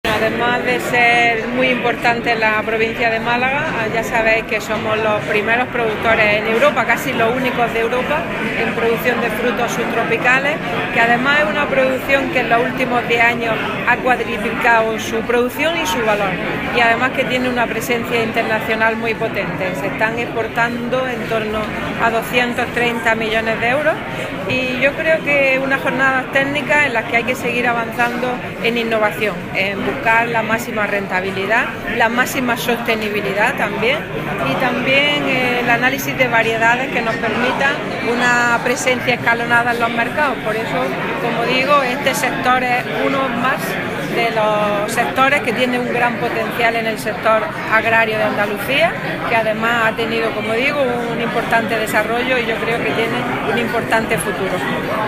Carmen Ortiz inaugura una jornada sobre producción de subtropicales en Vélez-Málaga
Declaraciones de Carmen Ortiz sobre